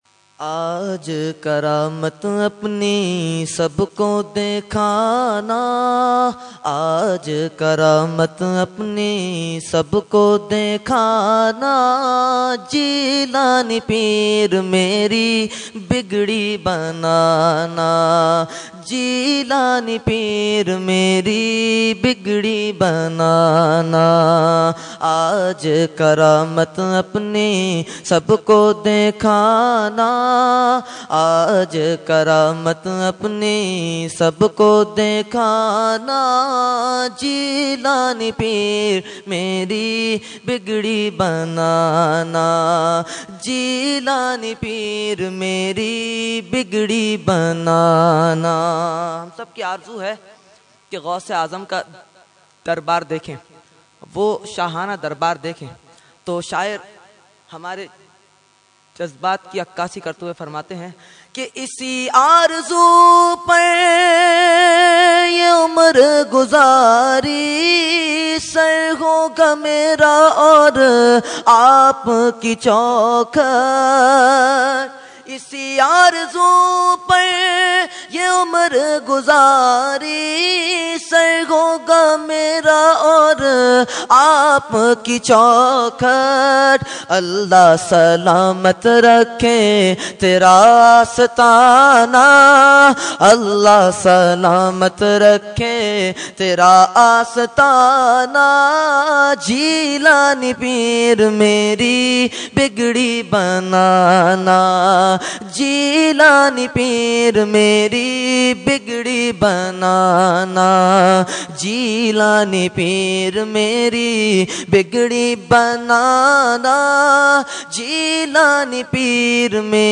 Category : Manqabat | Language : UrduEvent : 11veen Shareef 2014